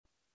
drum-slidertick.wav